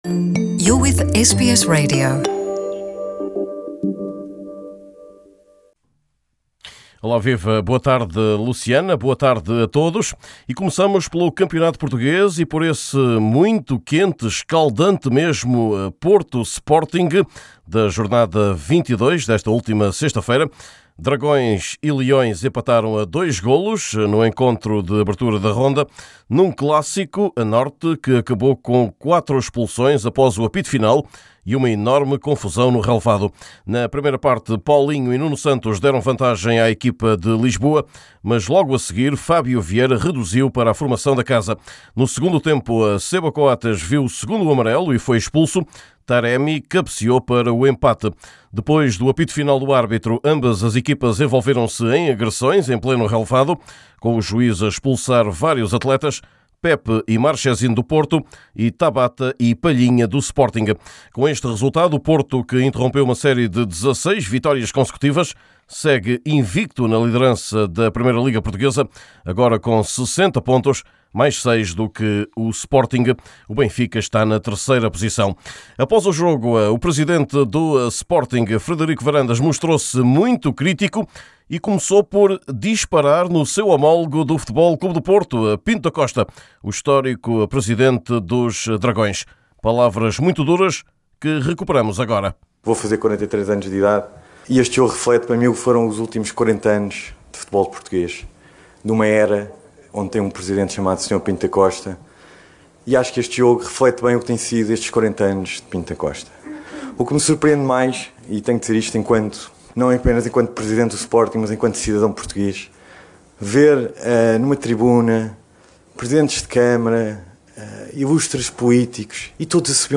Dragões e leões empatam (2-2) para o campeonato, num jogo que acabou com cinco expulsões. Confira entrevista com o presidente do Sporting Frederico Varandas.